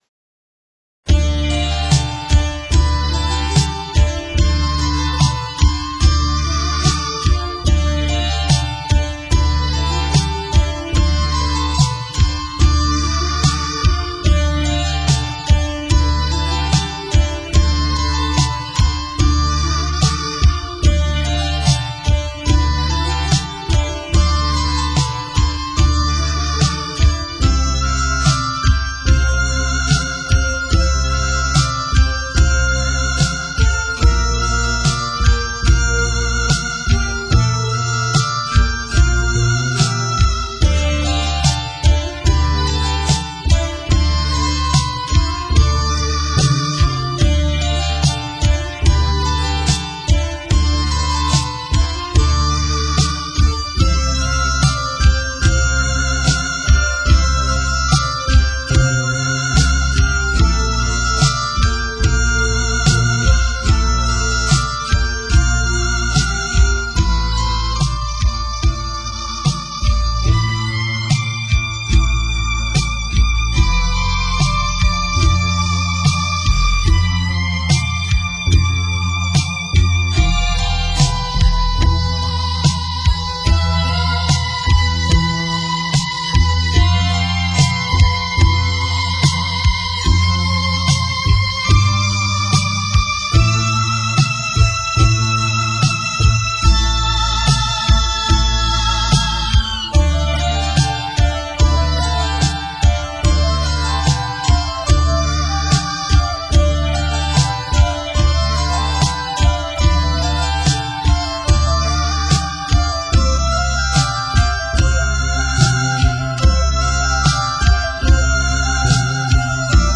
なんかキタロウみたいになってしまった。